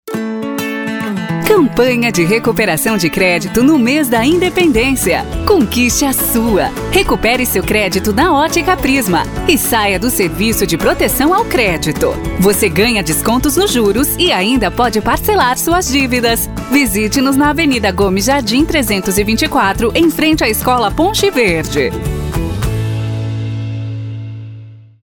• spot